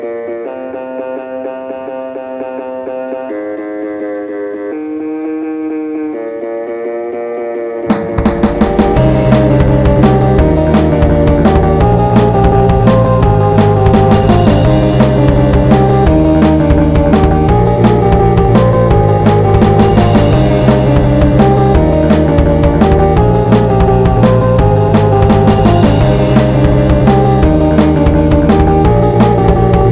Жанр: Электроника / Русские
# Electronic